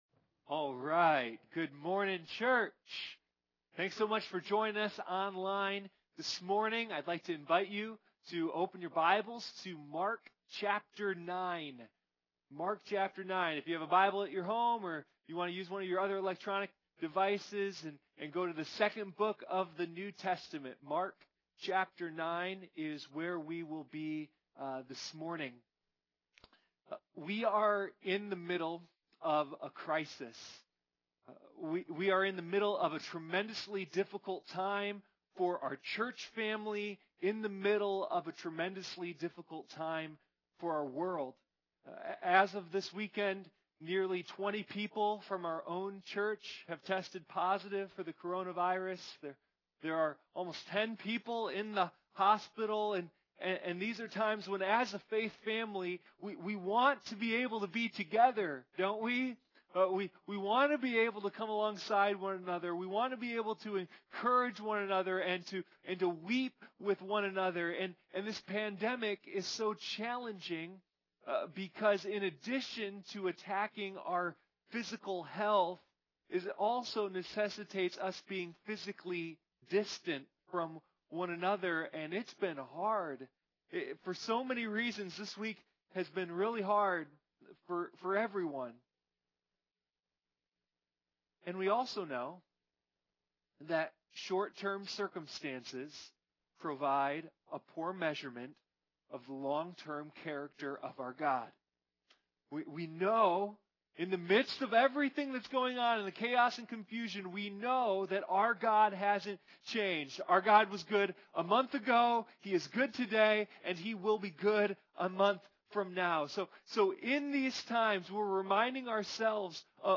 LET US HEAR FROM YOU After you’ve finished listening/watching to this sermon please connect with us by filling out our online Communication Card so we can hear from you and pray for you!